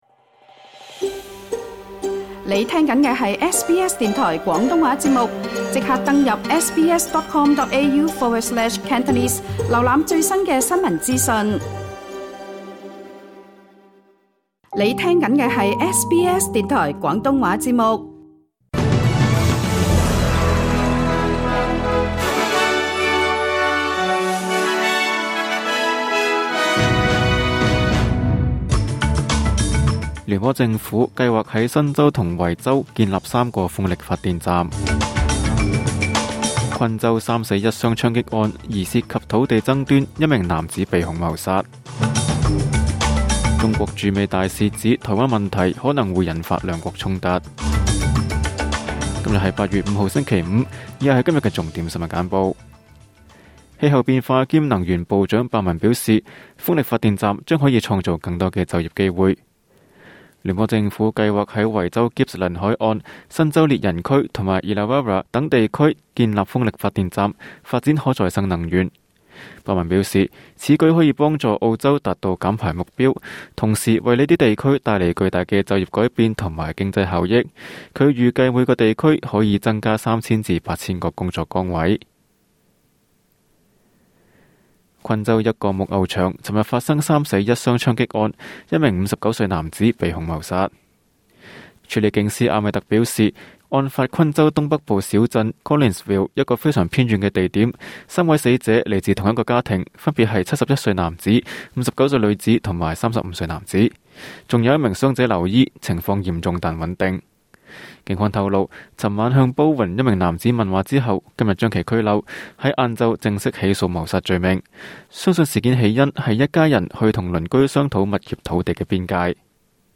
SBS 新聞簡報（8月5日） 06:48 SBS 廣東話節目新聞簡報 SBS廣東話節目 View Podcast Series Follow and Subscribe Apple Podcasts YouTube Spotify Download (6.23MB) Download the SBS Audio app Available on iOS and Android 請收聽本台為大家準備的每日重點新聞簡報。